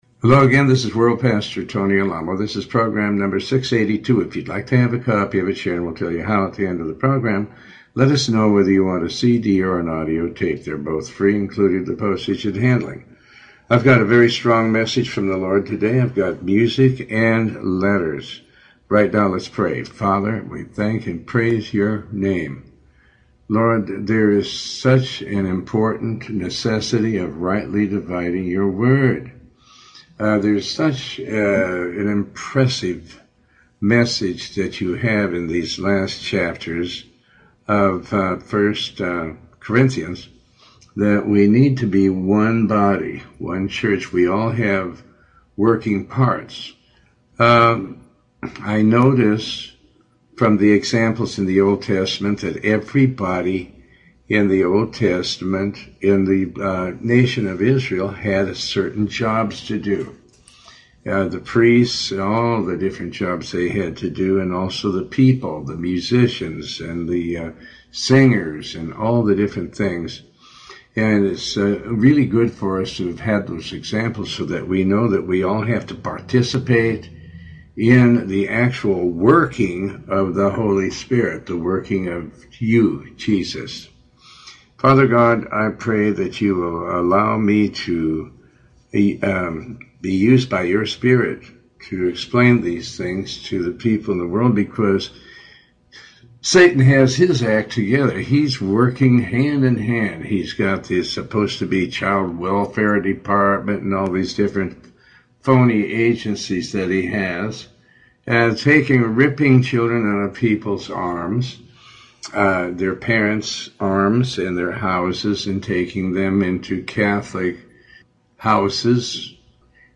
Tony Alamo Talk Show